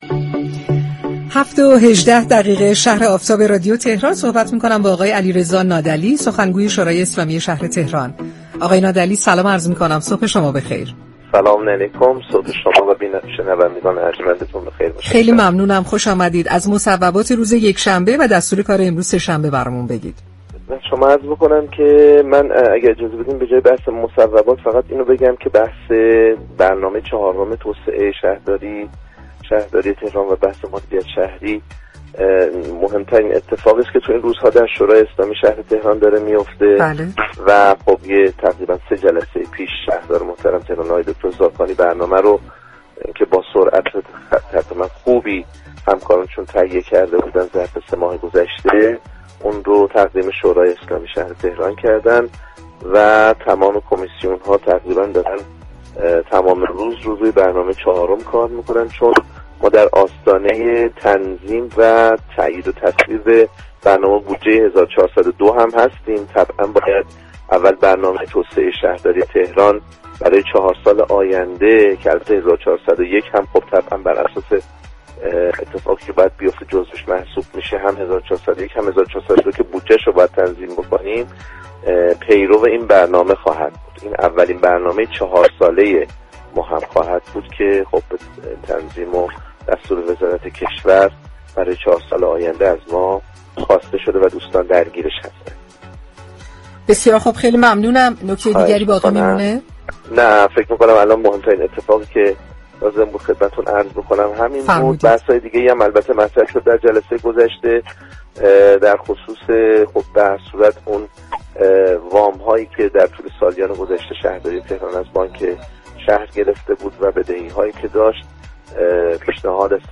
به گزارش پایگاه اطلاع رسانی رادیو تهران؛ علیرضا نادعلی سخنگوی شورای شهر تهران در گفت و گو با "شهر آفتاب" رادیو تهران گفت: برنامه چهارم توسعه شهرداری به شورای اسلامی شهر ارایه شده است و تمام كمیسیون‌ها در حال بررسی این برنامه هستند.